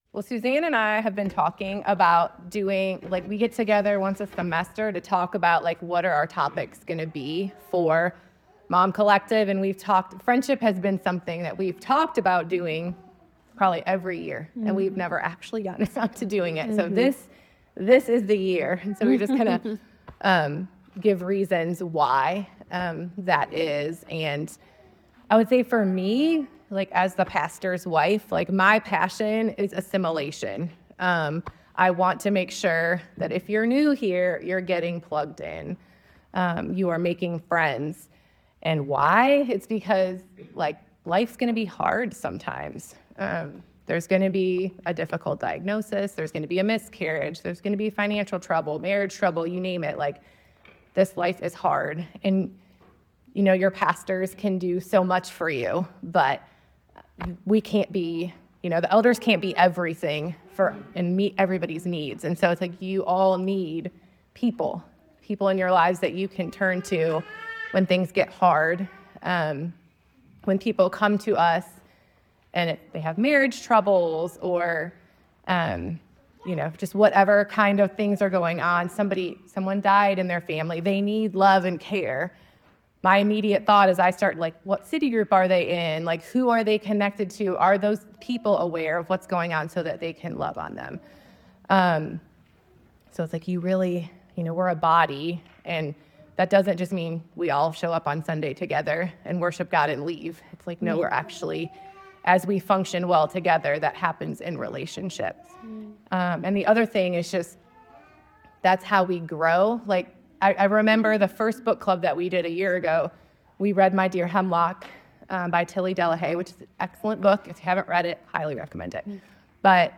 friendship-talk-at-mom-collective.mp3